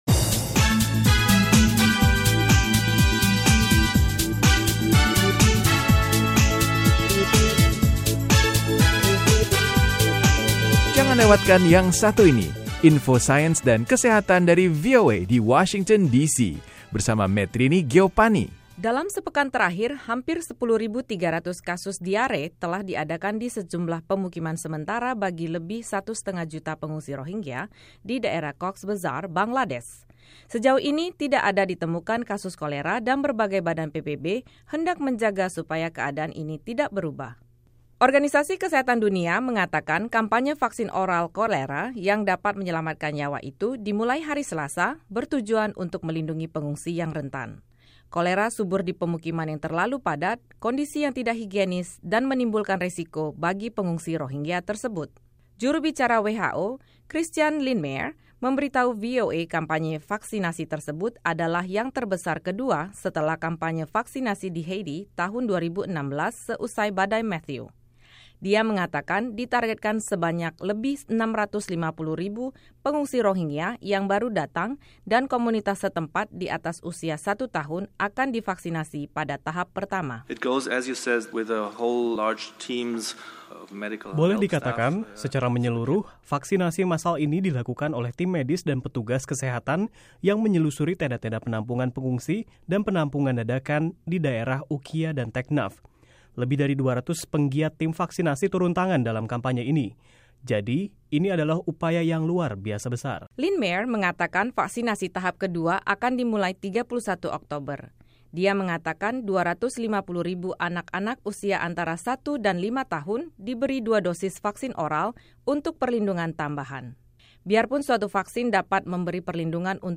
Kampanye vaksin kolera berbentuk pil atau cairan secara massal bagi ratusan ribu pengungsi Rohingya dan berbagai komunitas setempat berlangsung di Bangladesh. Laporan VOA dari Jenewa